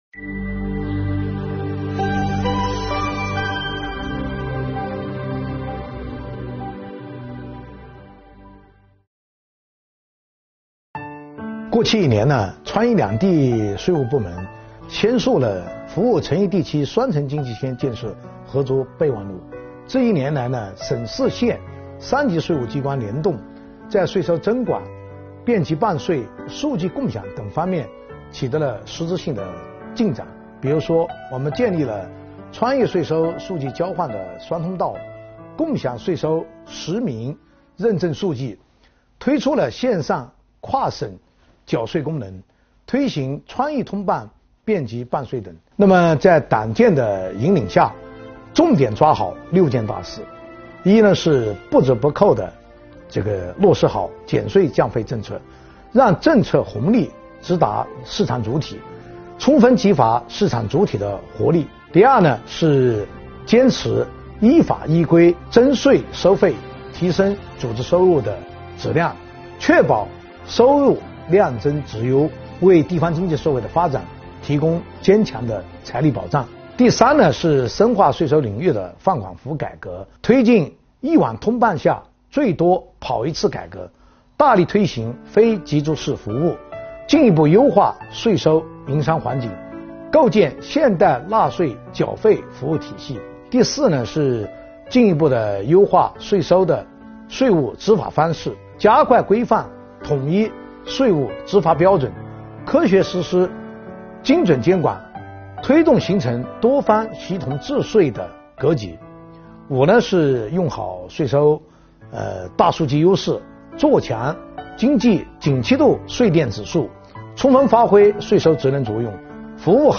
2021年是“十四五”开局之年，四川税收将如何开好局、起好步？2021年四川省两会期间，四川省政协委员，国家税务总局四川省税务局党委书记、局长李杰如是说。